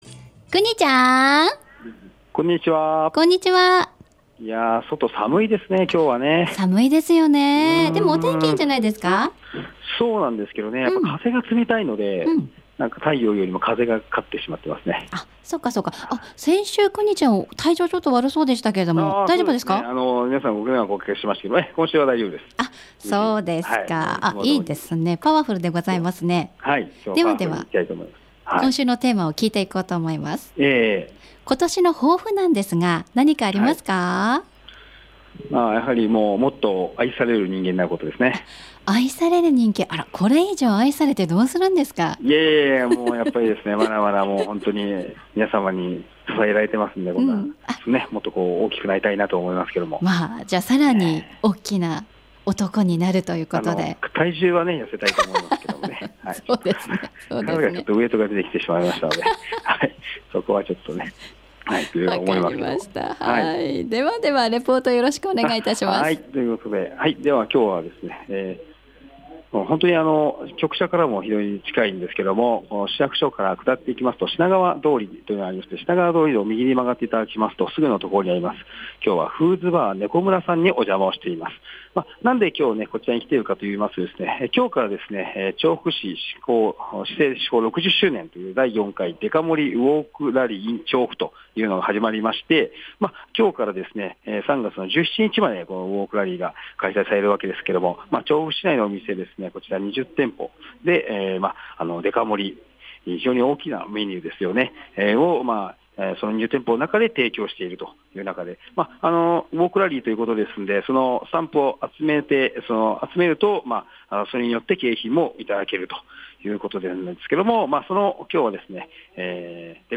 この猫村さんから中継をさせていただきました。 この調布デカ盛ウォークラリー今回は４回目で 調布市市制施行６０周年を飾るイベントであります。